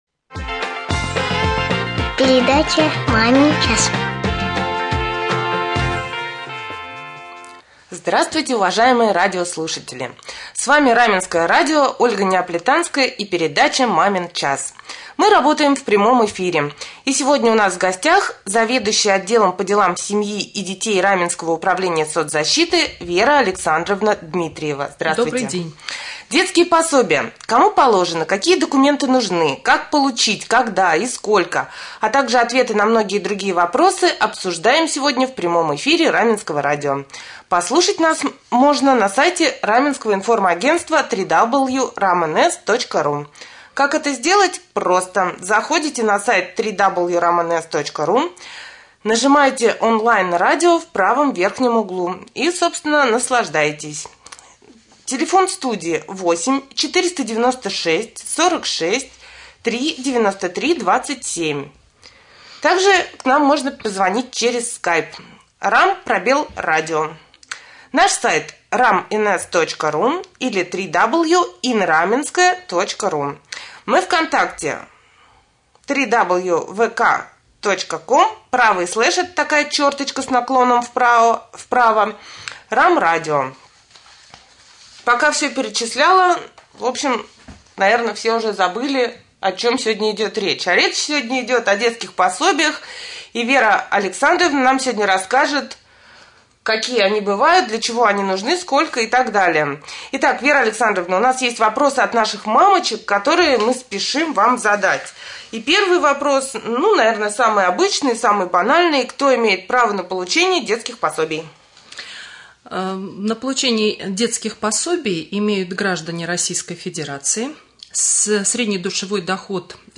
Проект «Мамин час» стартовал на Раменском радио в минувший понедельник 28 ноября.